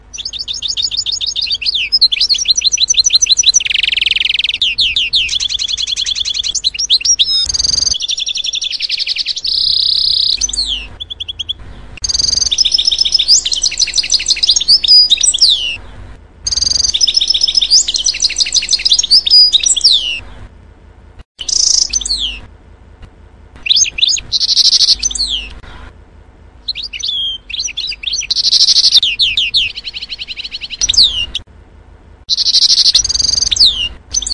金翅雀
描述：金翅鸟1311的最佳声音
Tag: 记录的家庭 录音花园 现场 记录